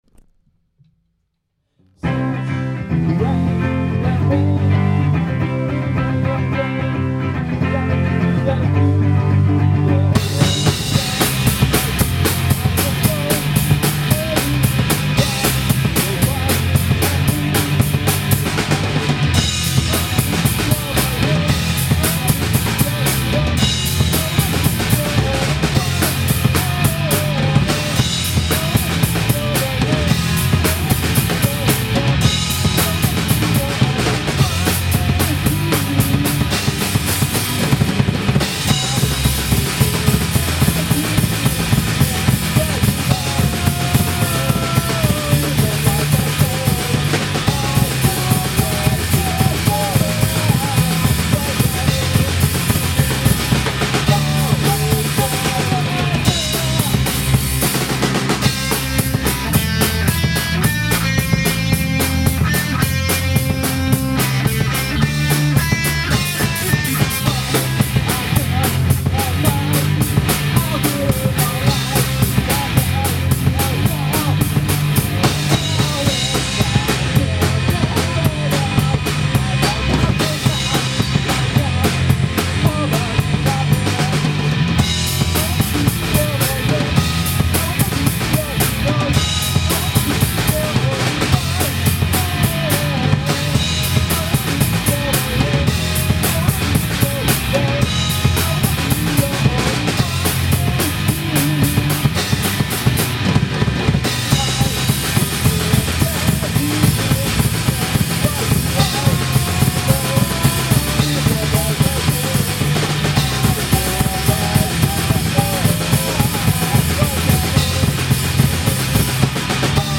メロコアなのかパンクなのか、3ピースバンド
スタジオ一発録りのDEMO音源と、今までのLIVE動画。